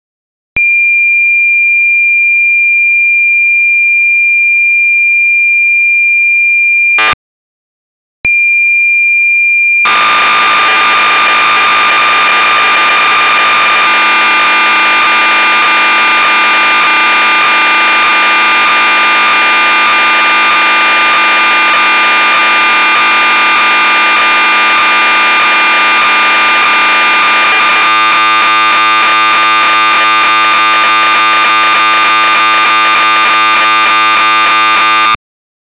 Cargador BASIC en formato audio para CLOAD (GPLv3).